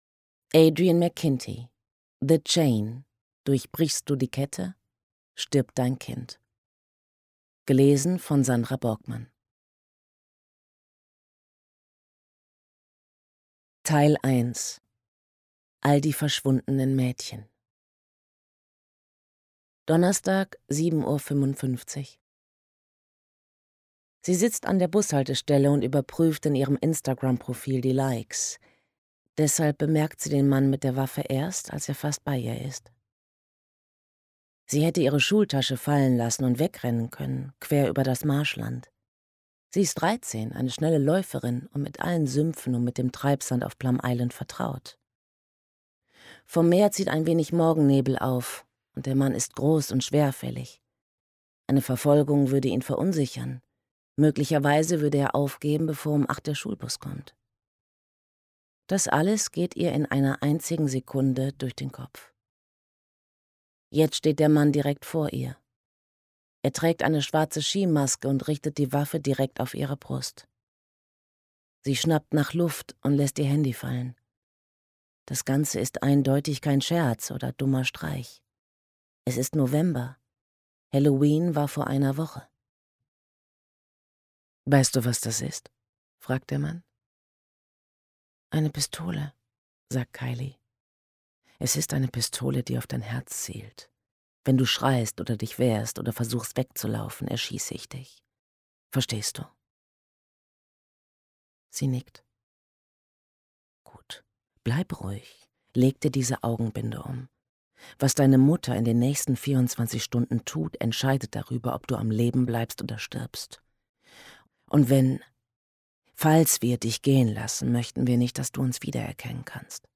Ungekürzte Lesung mit Sandra Borgmann (1 mp3-CD)
Sandra Borgmann (Sprecher)